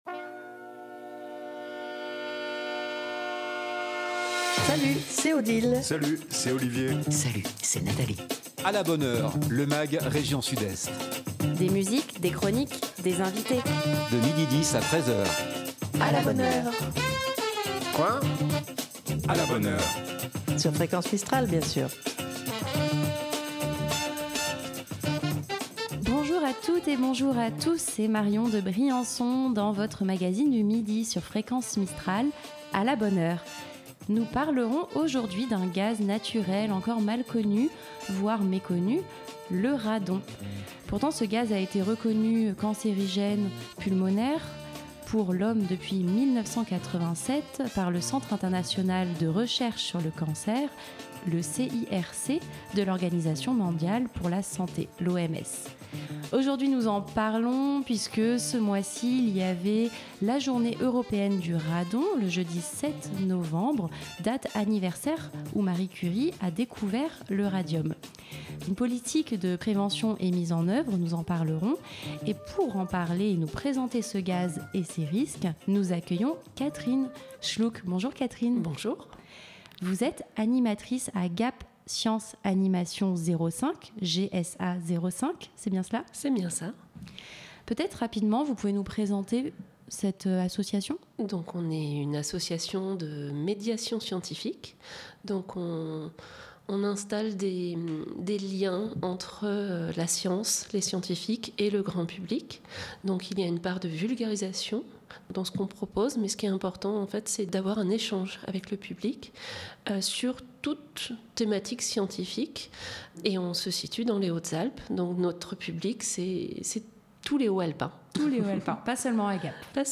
Du lundi au vendredi de 12h10 à 13h " À la bonne heure ! " Bienvenue dans le nouveau magazine région Sud-Est de Fréquence Mistral !
De Marseille à Briançon en passant par Manosque, sans oublier Dignes les bains, et Gap, un magazine régional, un Mag rien que pour vous, des invité.e.s en direct, des chroniques musique, cinéma, humour, littéraire, sorties et sur divers thèmes qui font l’actualité. Nous parlons aujourd'hui d'un gaz naturel encore mal connu, voire encore méconnu, le radon .